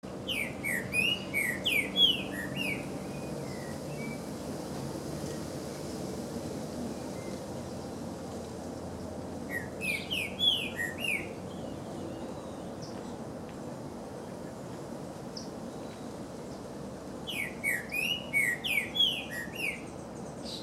Pepitero Verdoso (Saltator similis)
Nombre en inglés: Green-winged Saltator
Fase de la vida: Adulto
Localidad o área protegida: Reserva Ecológica Costanera Sur (RECS)
Condición: Silvestre
Certeza: Vocalización Grabada